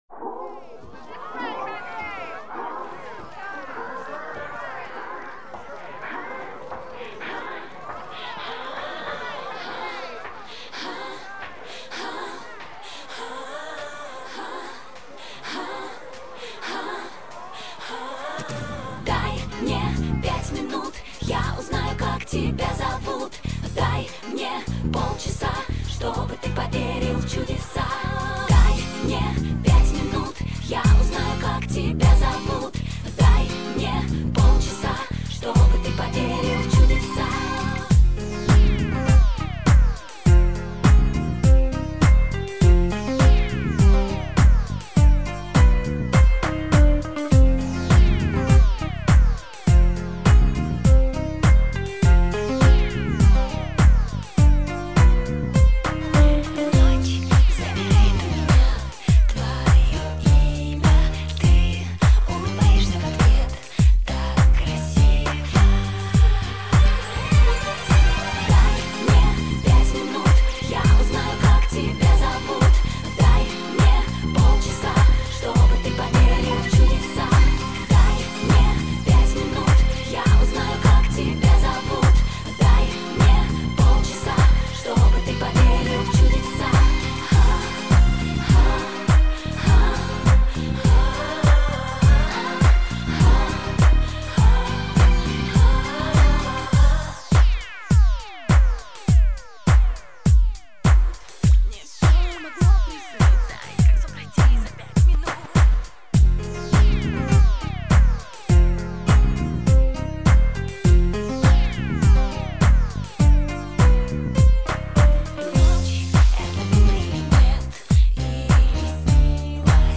минусовка версия 241061